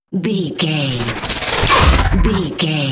Whoosh to hit sci fi
Sound Effects
dark
futuristic
intense